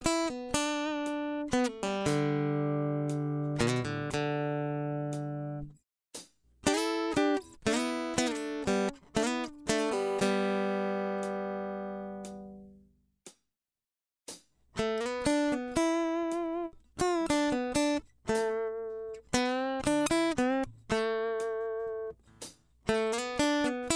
Minus Lead 12 String Guitar Rock 5:02 Buy £1.50